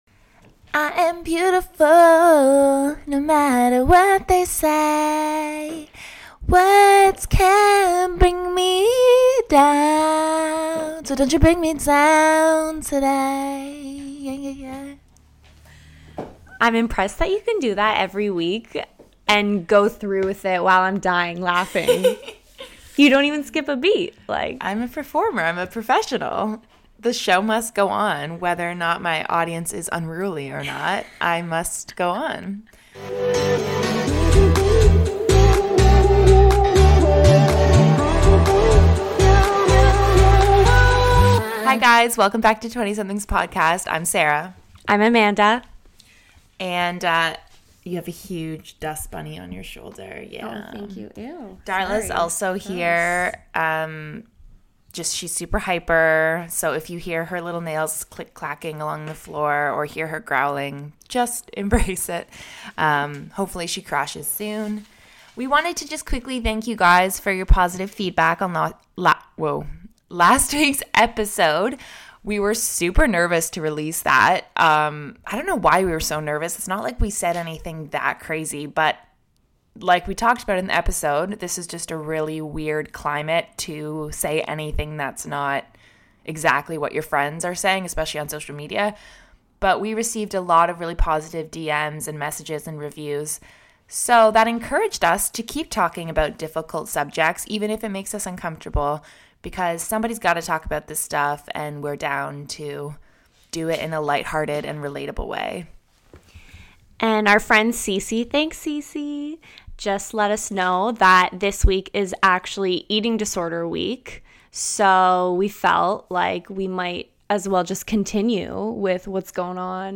They share some insights, advice and even have a few giggles along the way.